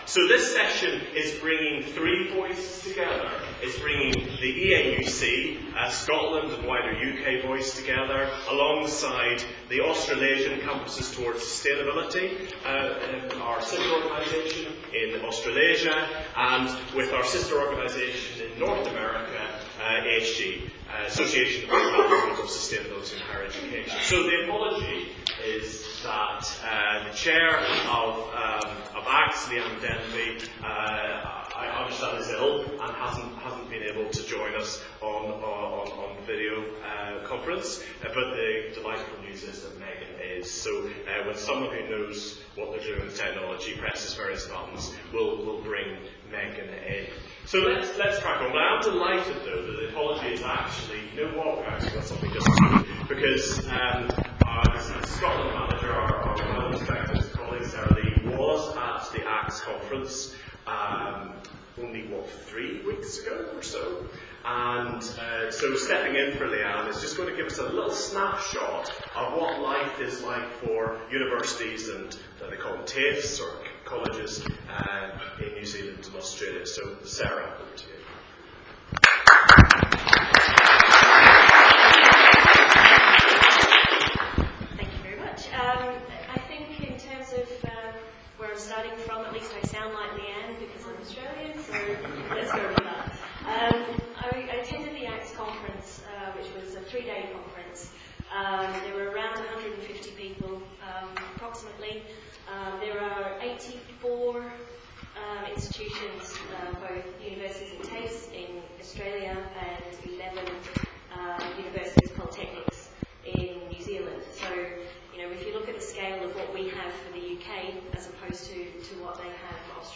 eauc-scotland_conference_2015_-_international_tertiary_education_priorities_and_challenges.mp3